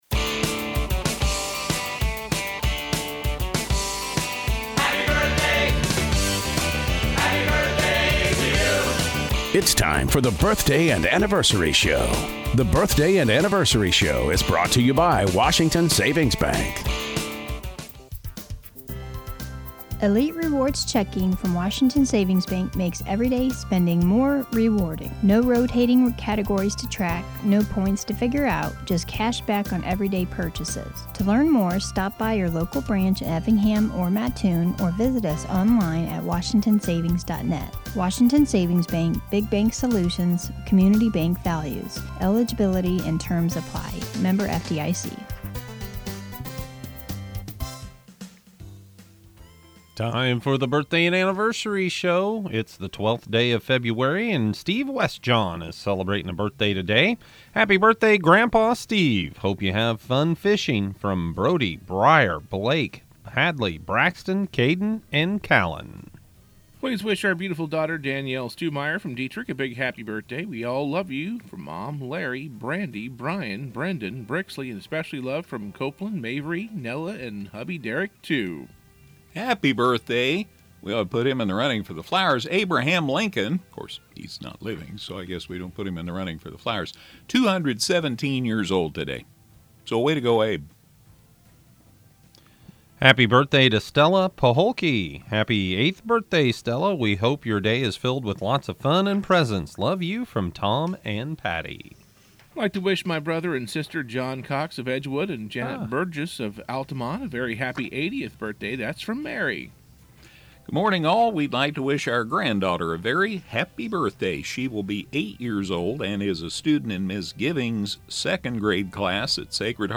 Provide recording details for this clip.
That success was the topic at Wednesday's meeting of Effingham Noon Rotary Club.